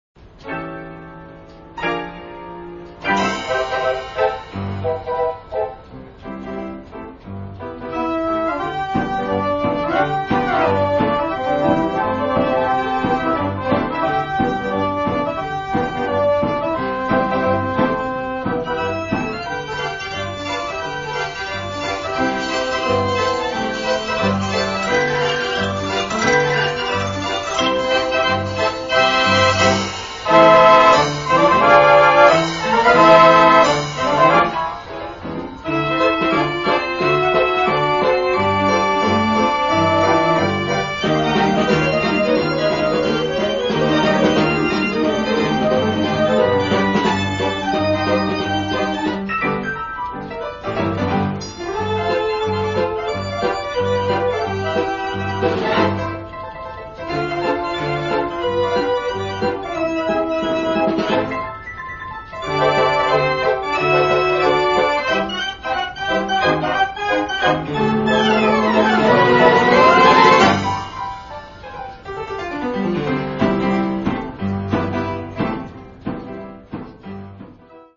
It is done straight into an audio-digital USB terminal called an audio-USB converter, and into a computer. These recordings have not been doctored, and are as true to life as I could get them.